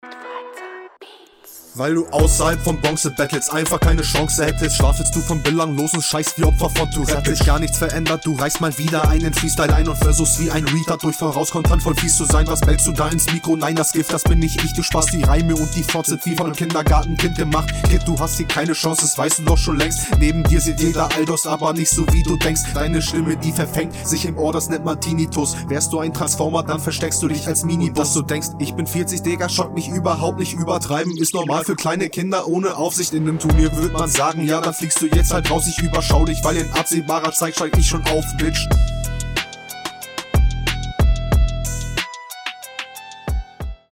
Klingt mit dem Beat hier direkt besser.